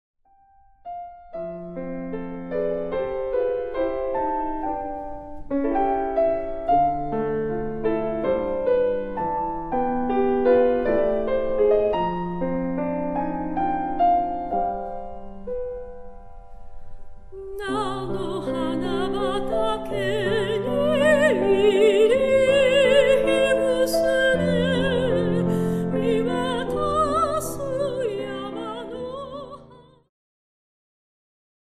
ジャンル POPS系
癒し系
歌・ピアノ
シタール